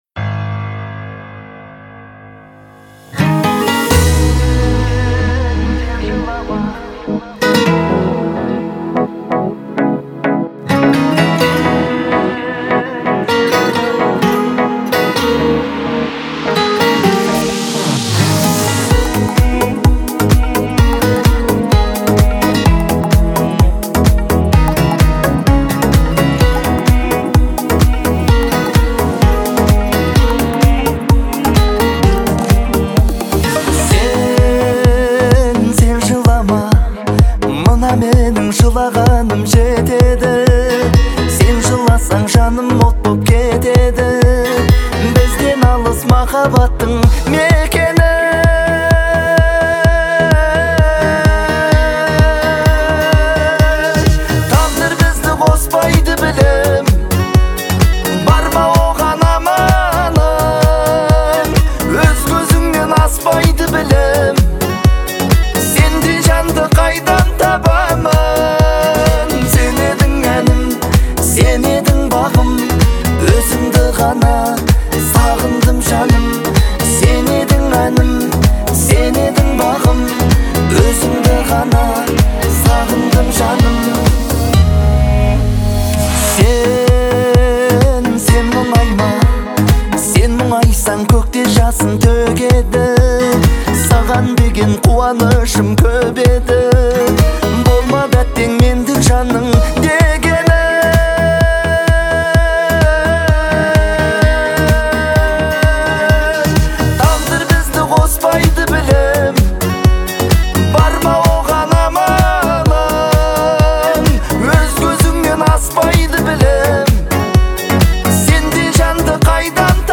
это яркий трек в жанре поп с элементами электронной музыки